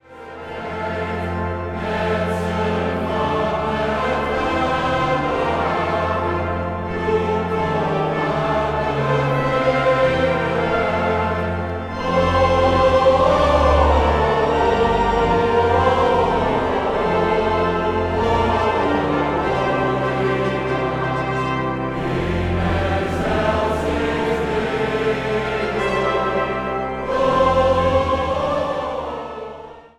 Zang | Mannenkoor
Zang | Mannenzang
Zang | Samenzang